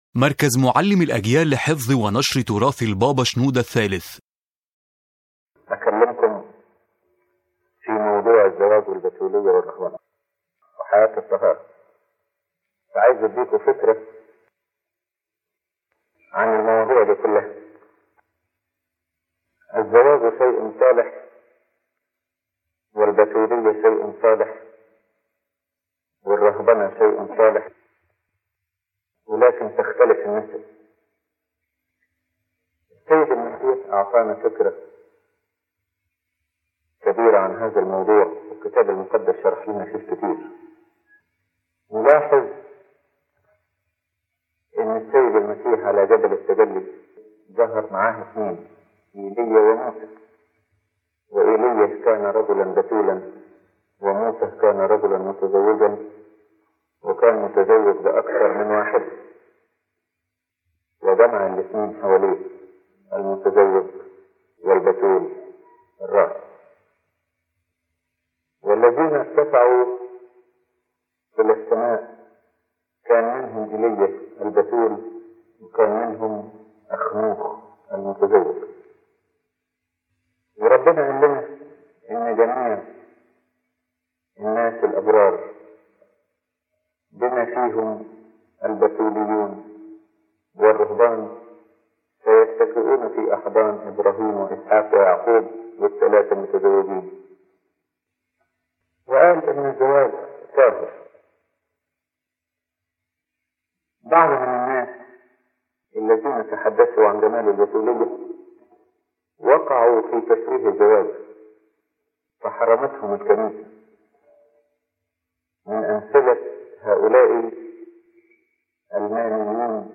A Talk on Marriage, Virginity, Monasticism, and the Life of Purity